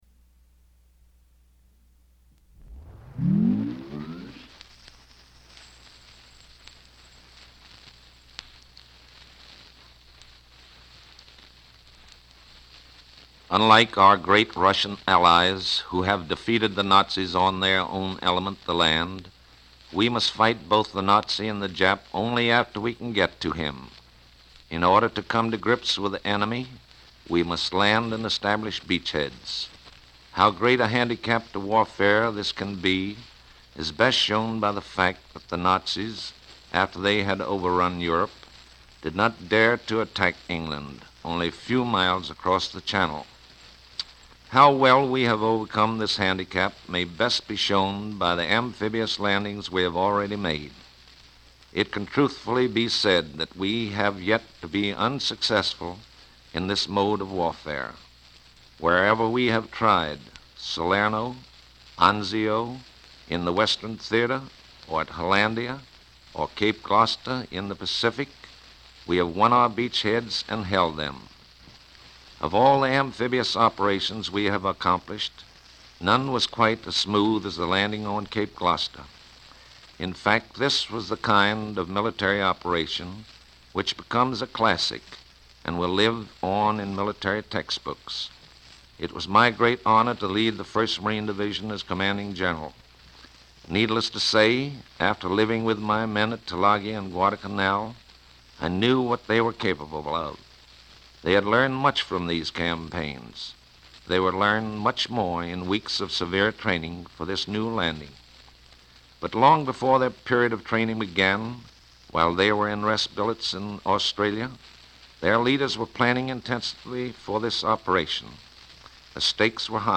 Our grandfather, Marine Major General Rupertus, commanded this battle and discussed it in an interview in 1944.
Note it stops in the middle, then continues.